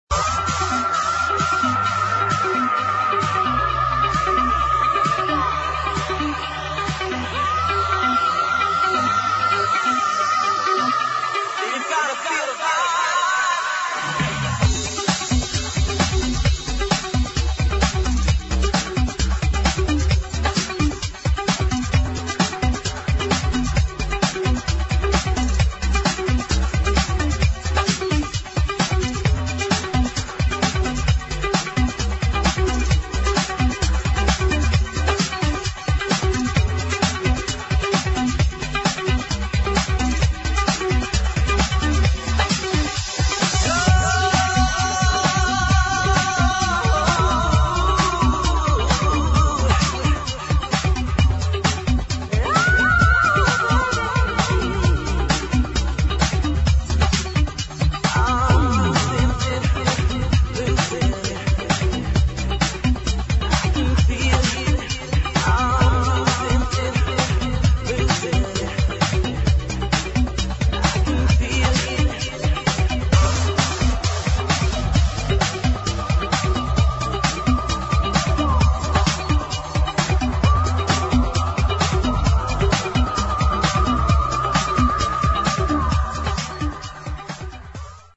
[ BREAKS ]
ブレイクス・ライクにリミックス！